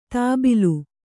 ♪ tābilu